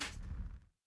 orni_attack.wav